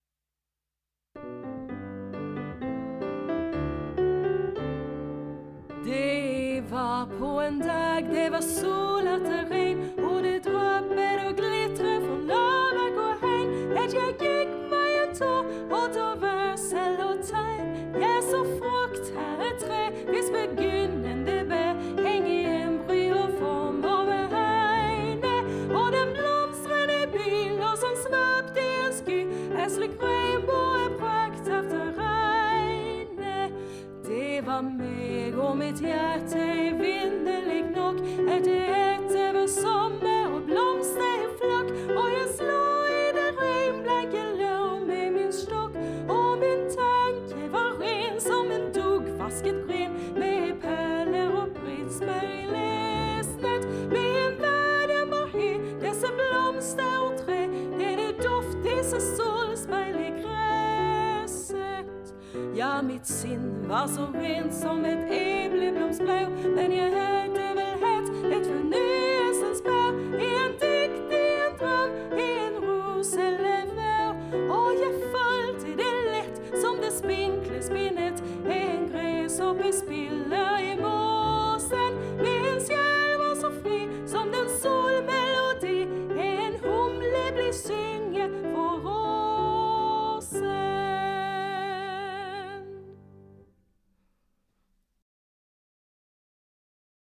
Musik och arrangemang och piano